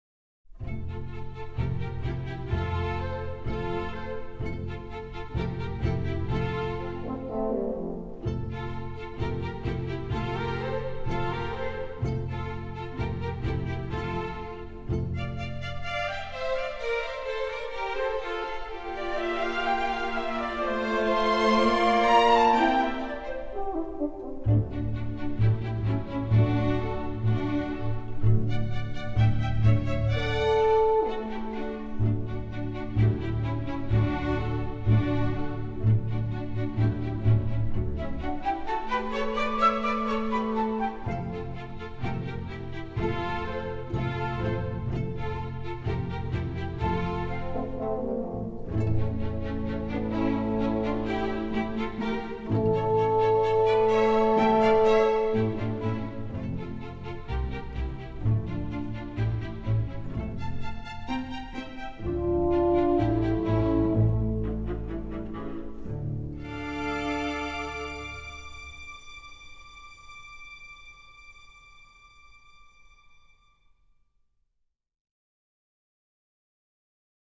广告片背景音乐